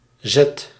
Ääntäminen
Ääntäminen letter name: IPA: /zɛt/ Haettu sana löytyi näillä lähdekielillä: hollanti Käännöksiä ei löytynyt valitulle kohdekielelle.